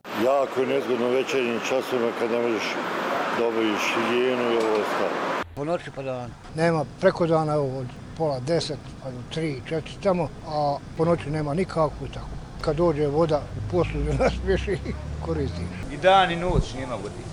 Građani Sarajeva o nestašicama vode